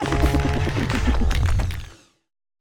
Grito de Revavroom.ogg
Grito_de_Revavroom.ogg.mp3